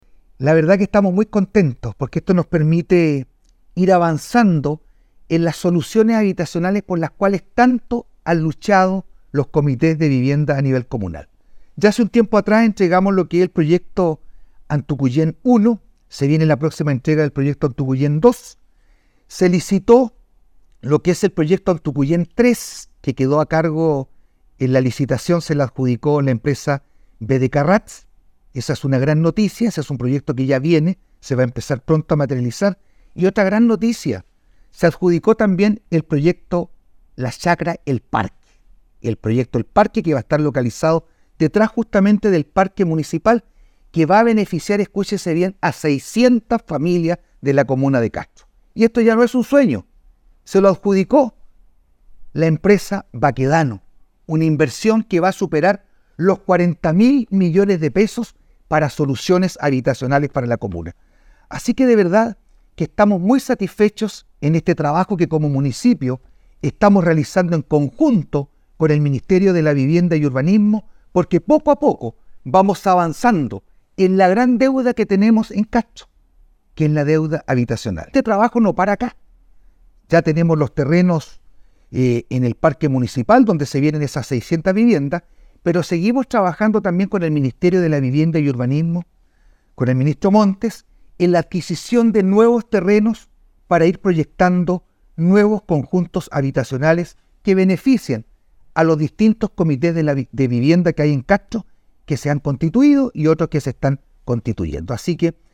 ALCALDE-VERA-PROYECTOS-HABITACIONALES.mp3